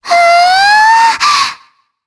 Shea-Vox_Happy4_jp.wav